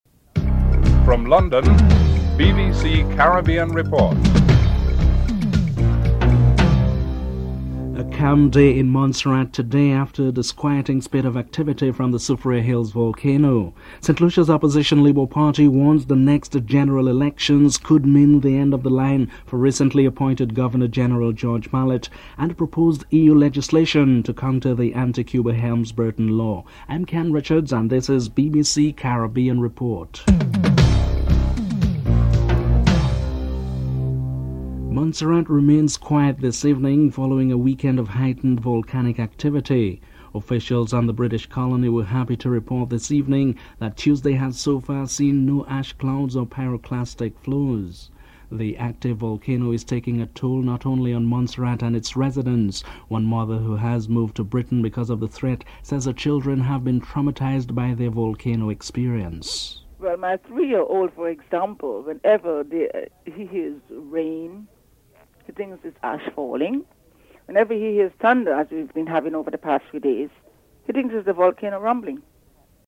1. Headlines (00:00-00:34)
3. The high level two man team appointed to mediate in the St. Kitts and Nevis secession issue has met with Nevisian Premier Vance Amory. Sir Shridath Ramphal is interviewed (08:14-10:02)